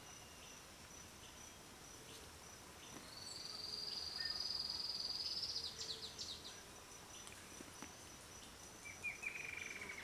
Tiluchi Enano (Terenura maculata)
Nombre en inglés: Streak-capped Antwren
Fase de la vida: Adulto
Localidad o área protegida: Reserva Privada y Ecolodge Surucuá
Condición: Silvestre
Certeza: Vocalización Grabada